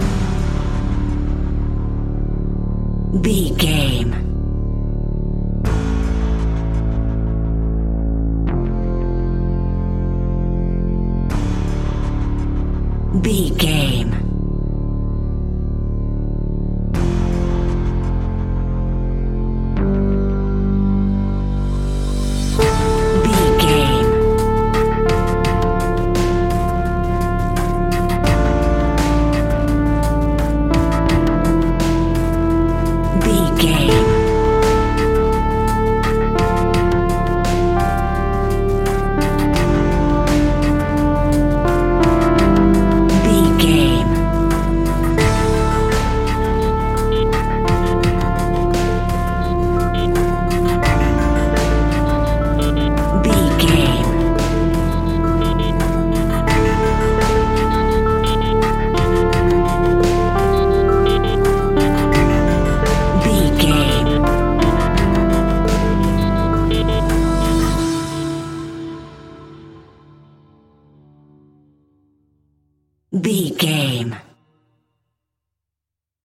Aeolian/Minor
scary
tension
ominous
dark
disturbing
haunting
eerie
piano
strings
drums
percussion
synthesiser
ticking
electronic music
Horror Synths